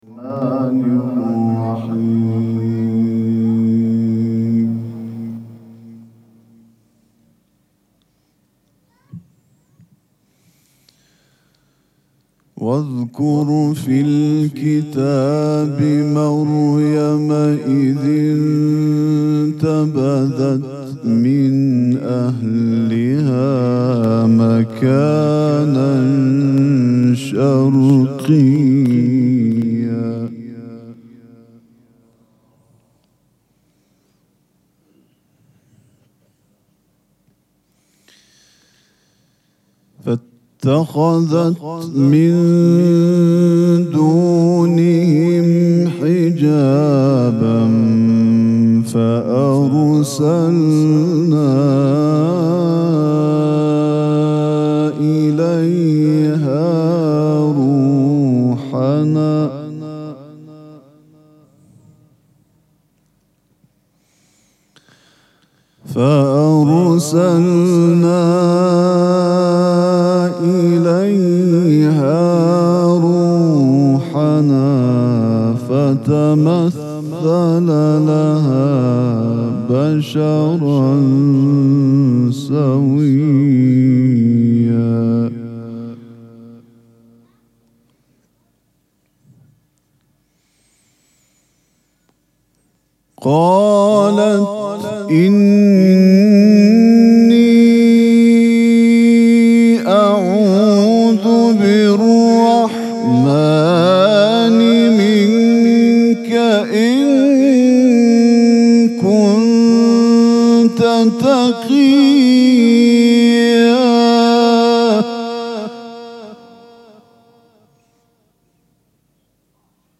شب سوم مراسم عزاداری دهه دوم فاطمیه ۱۴۴۶
سبک اثــر قرائت قرآن